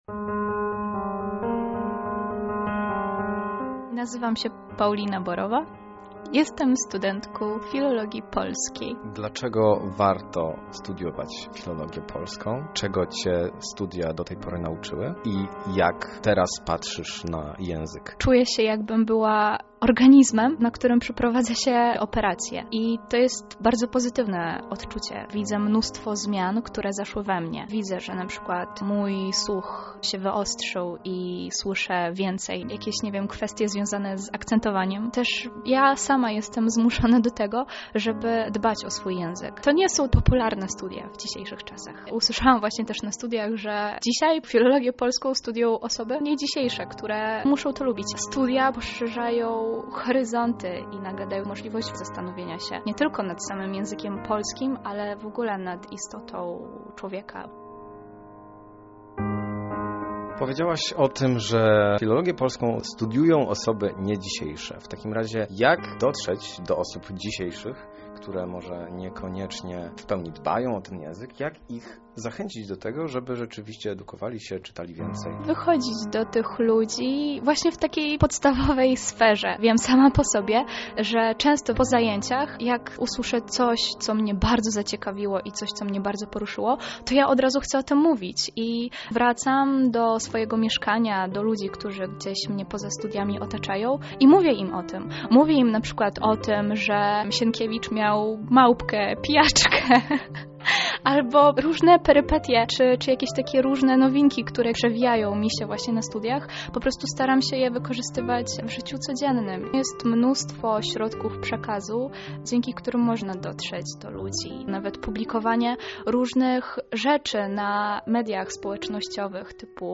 W tym celu przeprowadził rozmowę ze studentami filologii polskiej, którzy na obecną sytuację patrzą odrobinę inaczej.